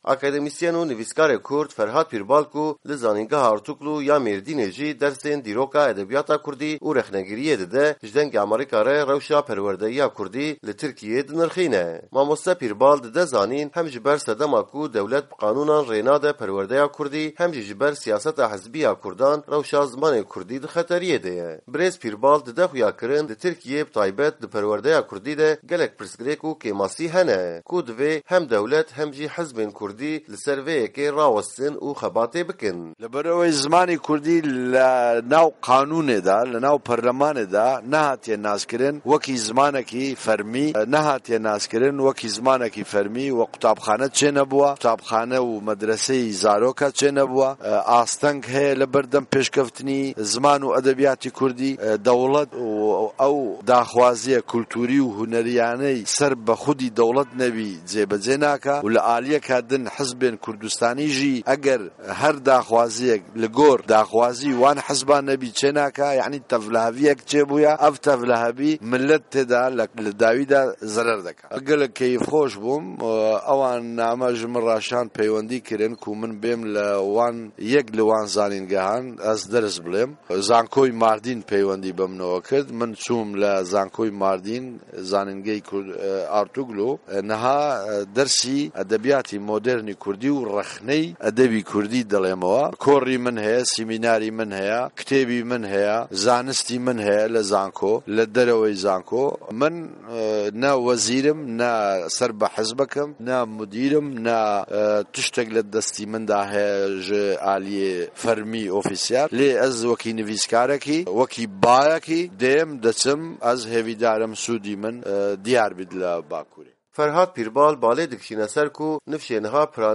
Hevpeyvîn bi Ferhad Pîrbal re